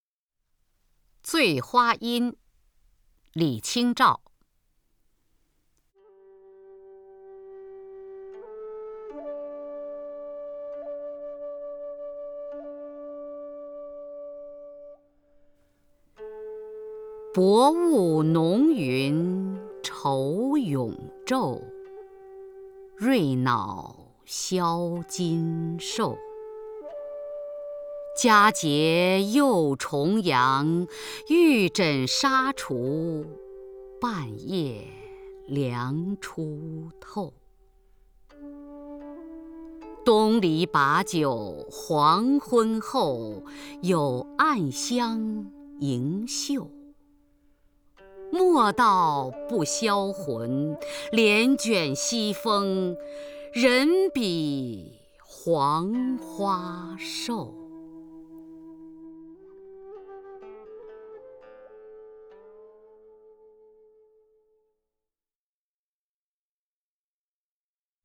首页 视听 名家朗诵欣赏 雅坤
雅坤朗诵：《醉花阴·薄雾浓云愁永昼》(（南宋）李清照)